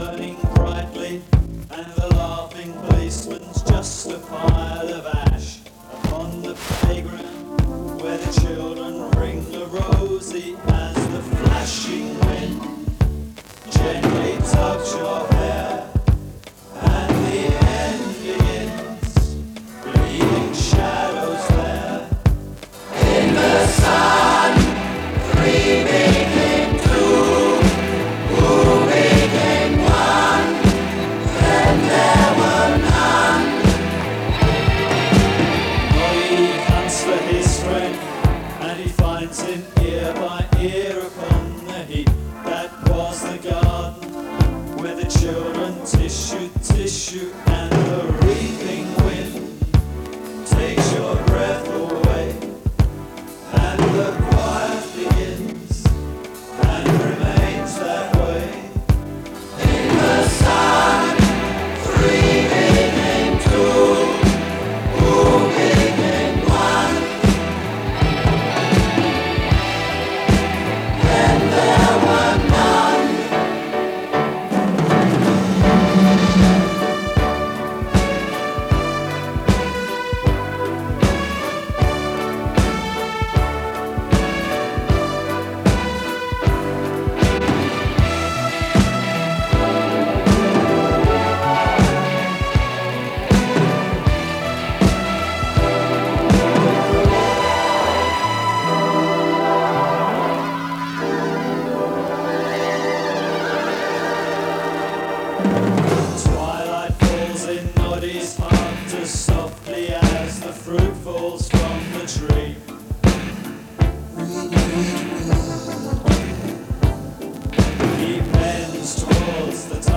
Recorded in South London from 90.2MHz in mono. There are some crackles in places. 125MB 136mins